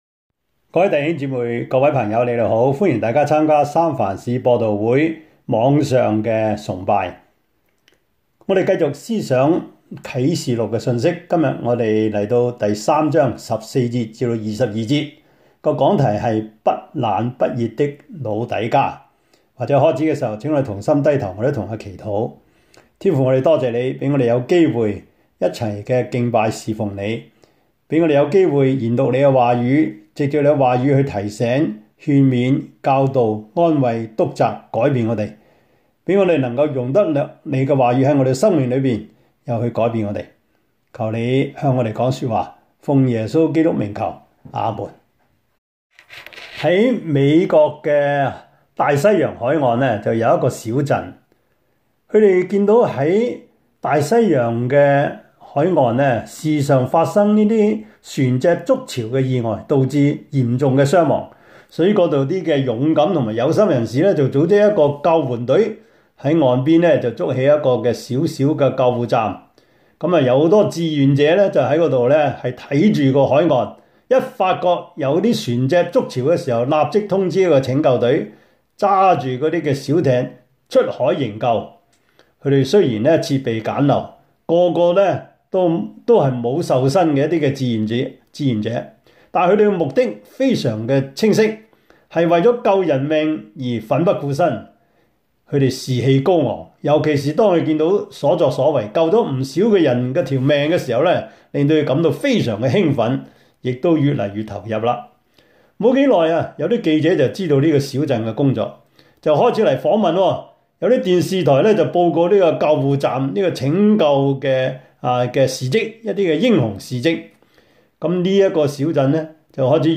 Service Type: 主日崇拜
Topics: 主日證道 « 面對罪惡的態度 處理罪惡的行動 »